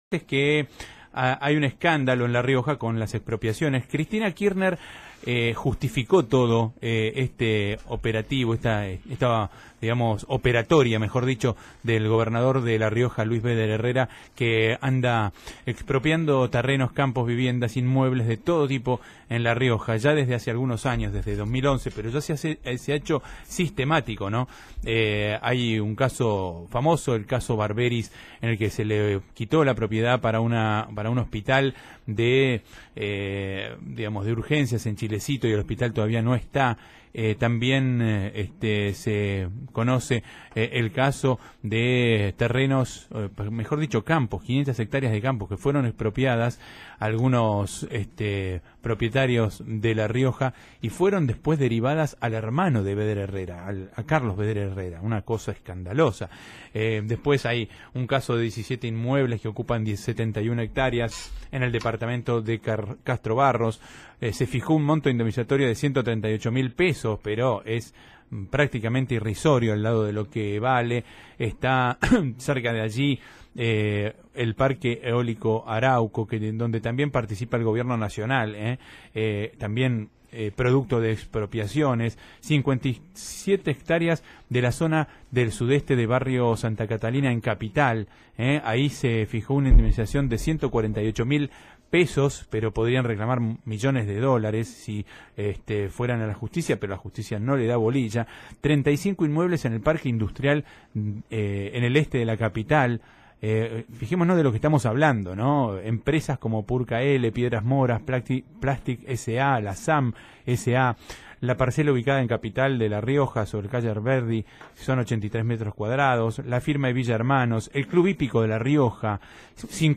En el programa también participó el diputado nacional radical, Julio Martínez.
Según la página web de Radio El Mundo, unas cuantas verdades es un programa de información política y económica que busca explicar aquellos aspectos ocultos o no conocidos de las noticias que leemos todos los días en los diarios.